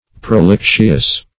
Search Result for " prolixious" : The Collaborative International Dictionary of English v.0.48: Prolixious \Pro*lix"ious\, a. Dilatory; tedious; superfluous.